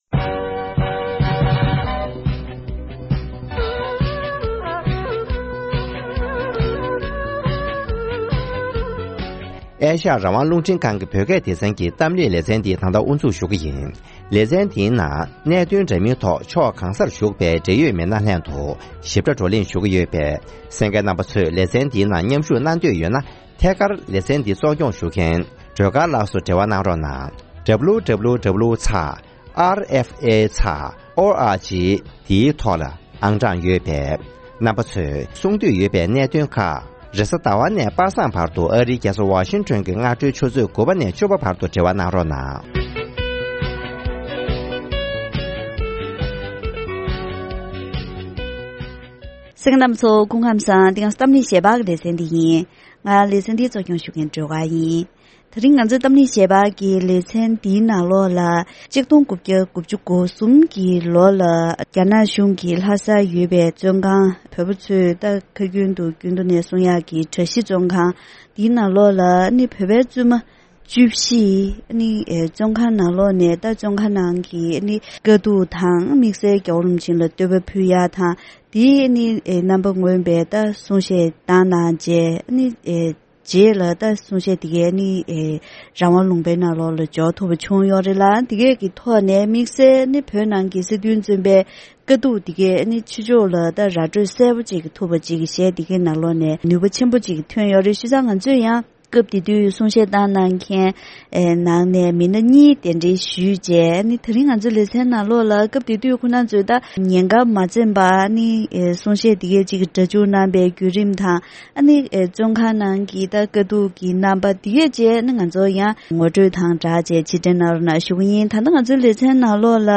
༡༩༩༣ལོར་རྒྱ་ནག་གི་བཙོན་ཁང་ནང་ཡོད་བཞིན་པའི་བུད་མེད་སྲིད་དོན་བཙོན་པ་ཁག་ཅིག་གིས་གཞས་ཀྱི་རྣམ་པའི་ཐོག་ནས་བཙོན་ཁང་ནང་གི་དཀའ་སྡུག་ངོ་སྤྲོད་གནང་ཡོད་པ།